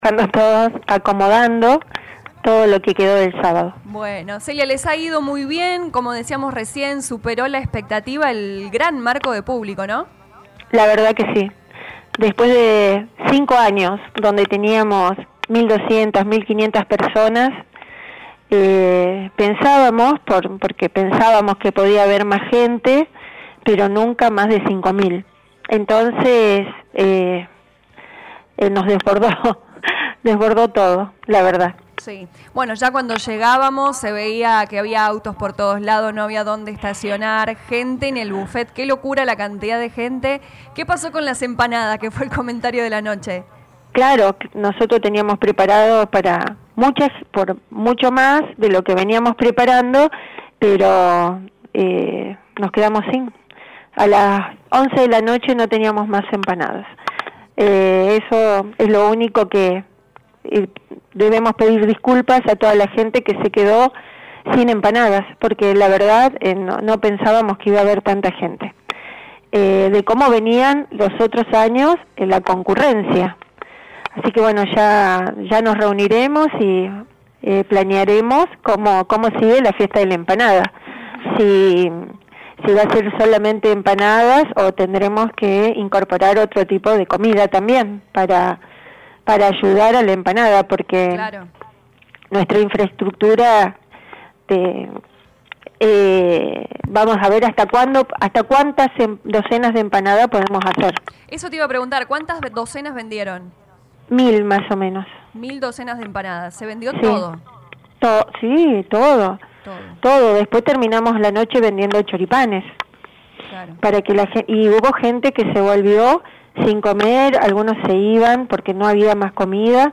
En diálogo con LA RADIO 102.9 FM la intendente Celia Giorgis confirmó que más de 5.500 personas asistieron y que rapidamente agotaron la propuesta gastronómica del evento, motivando a los organizadores a buscar opciones alternativas.